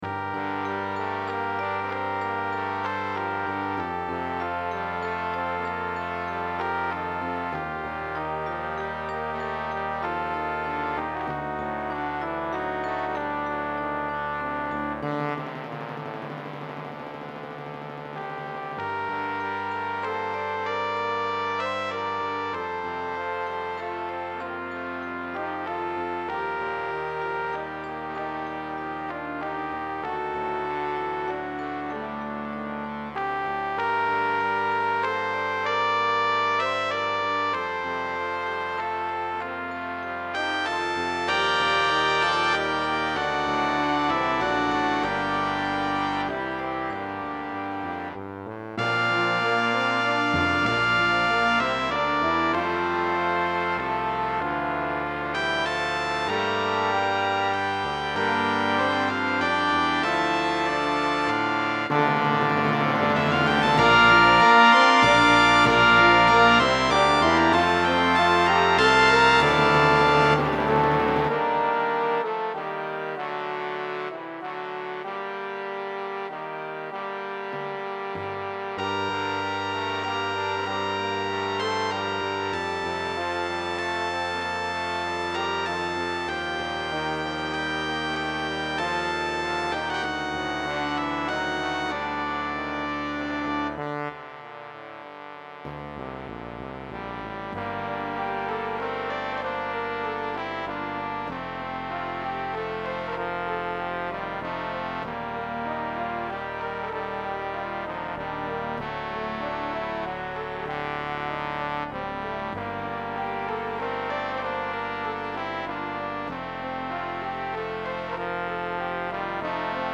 is a traditional English folk song.